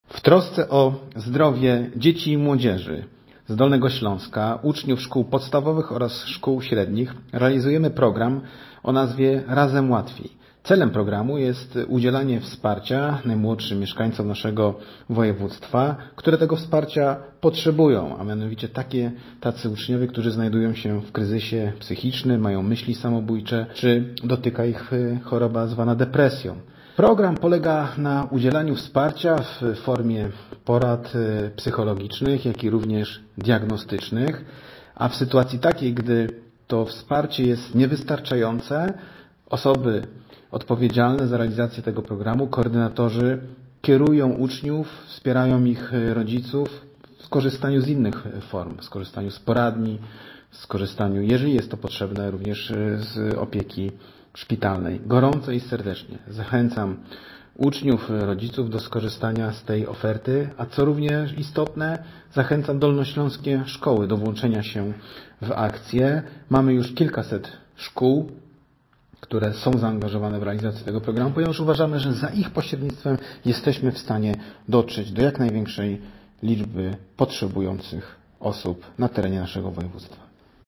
-Już kilkaset szkół dołączyło do programu. Zachęcam uczniów i rodziców do udziału w akcji, mówi Marcin Krzyżanowski – Wicemarszałek Województwa Dolnośląskiego.